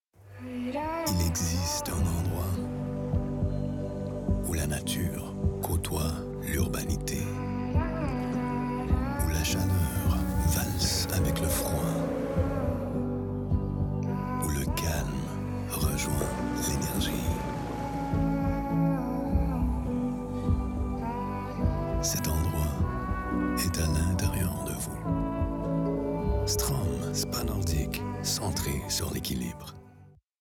Commercial (Strom) - FR